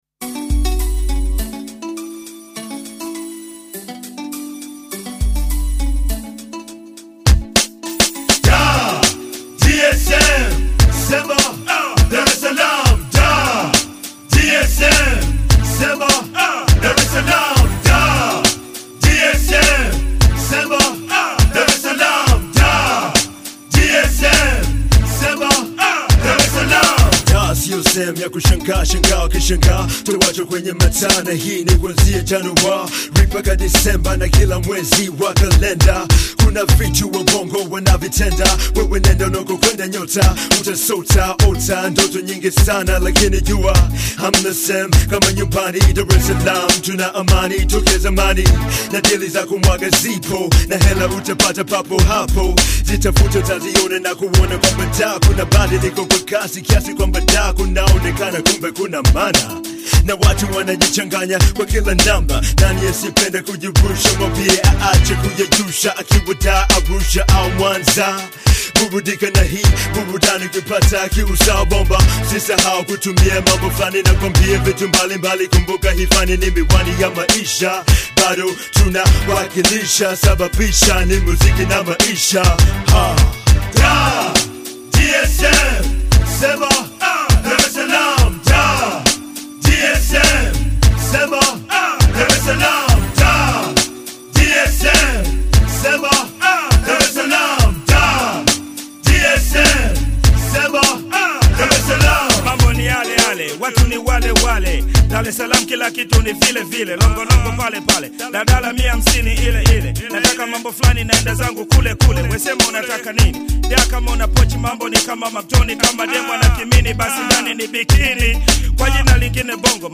Bongo fleva has come a long way.